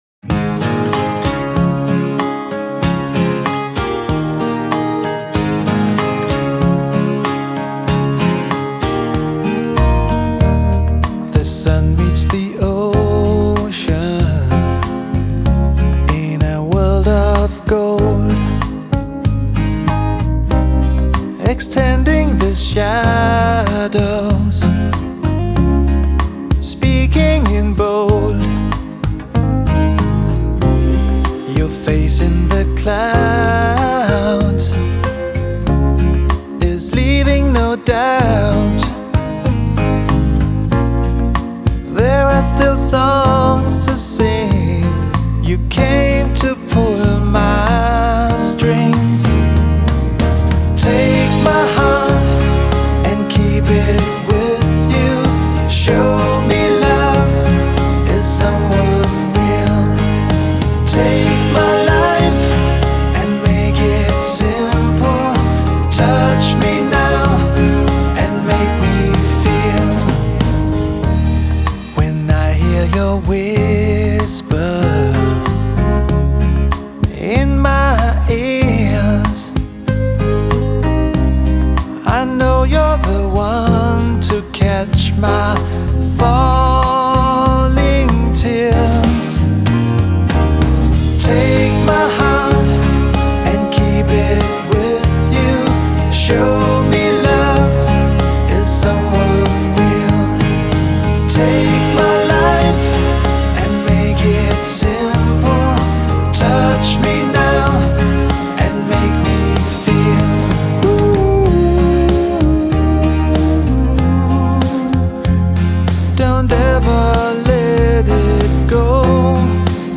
И напоследок- отличная баллада от этой классной группы.